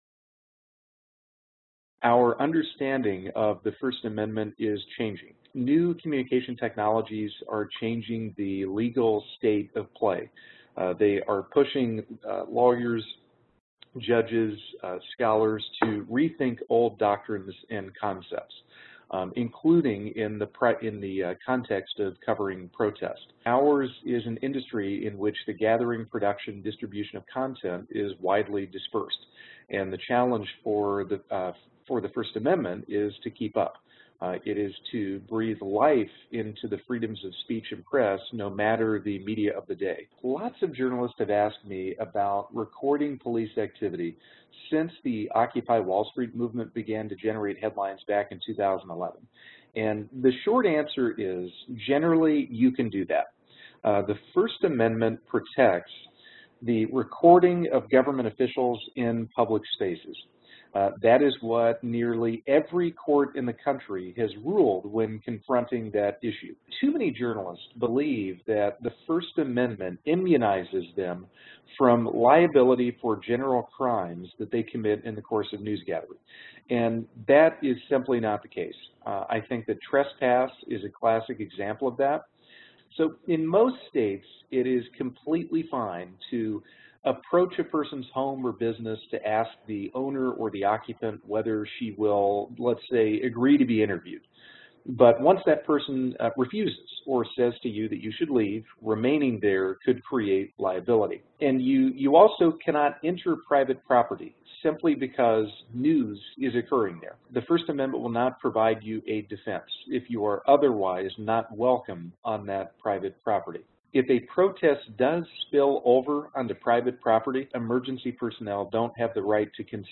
Webinar: Your rights as a journalist when covering a US protest